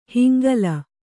♪ hingala